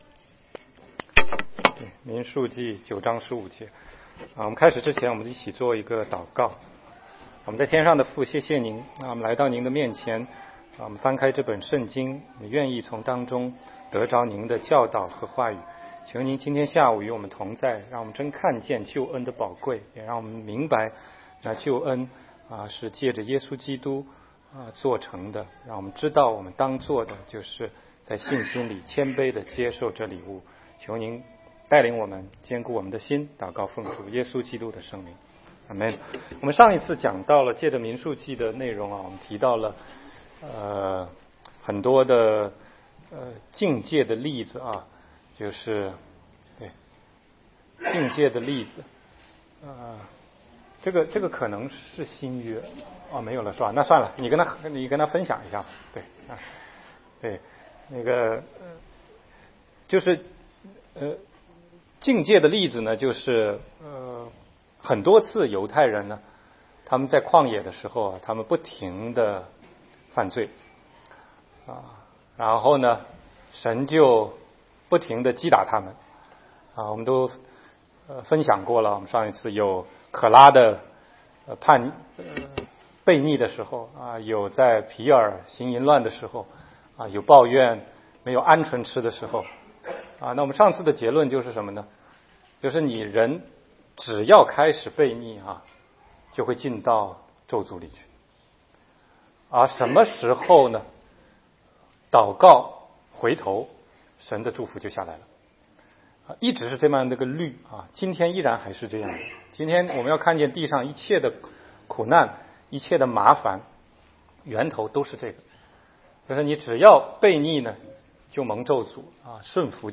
16街讲道录音 - 仰望铜蛇，接受救恩